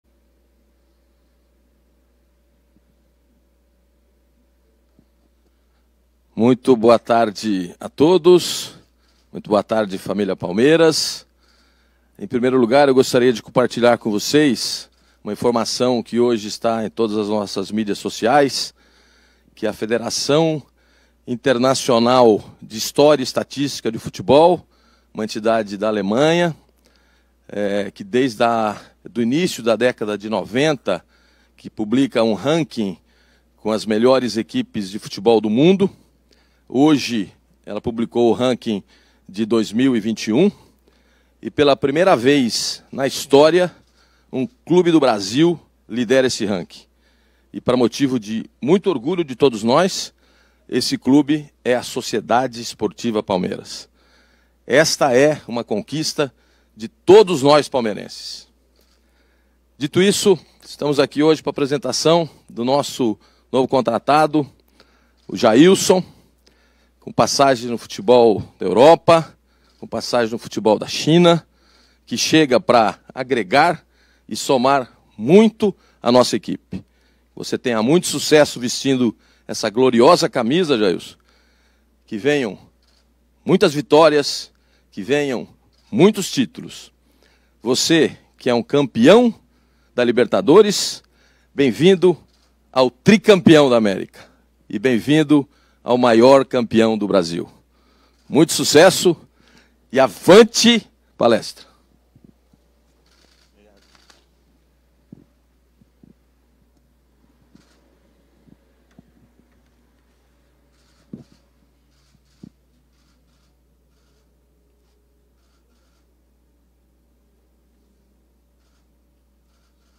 Confira abaixo a apresentação oficial do volante Jailson:
APRESENTACAO-_-JAILSON.mp3